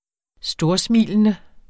Udtale [ -ˌsmiˀlənə ]